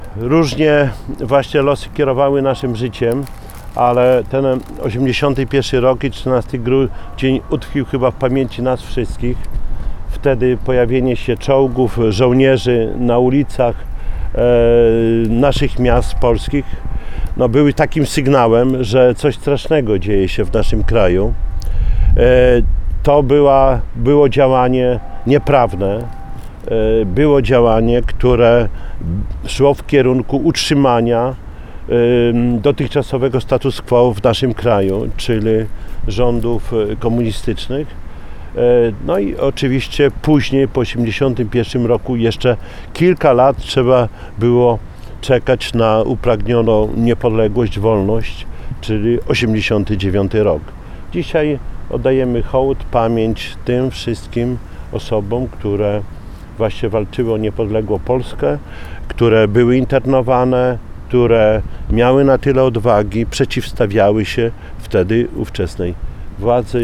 Kwiaty pod Dębem Wolności w centrum Suwałk złożyły w piątek (13.12), w rocznicę wprowadzenia stanu wojennego, władze miasta na czele z prezydentem, Czesławem Renkiewiczem. Włodarz przypomniał wydarzenia sprzed 38 lat, kiedy to generał Wojciech Jaruzelski ogłosił stan wojenny, na ulicach pojawiły się czołgi, a działacze opozycyjni zostali uwięzieni.